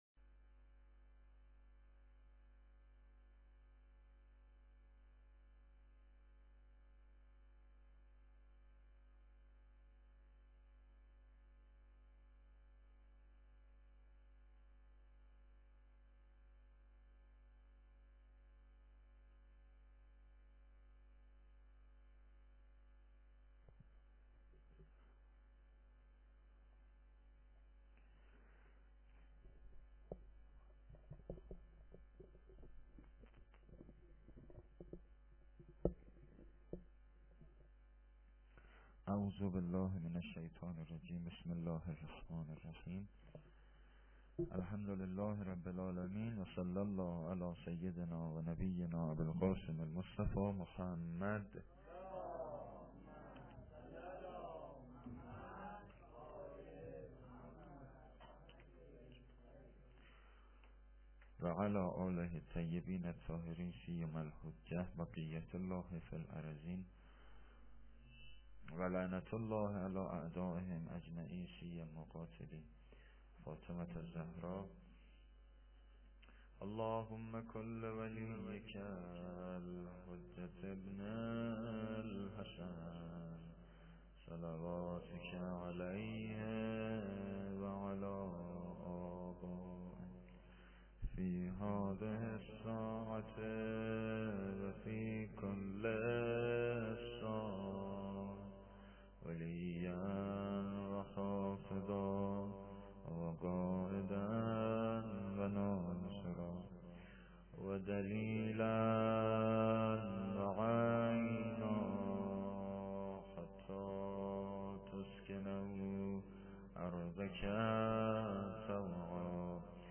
sokhanrani-8.lite.mp3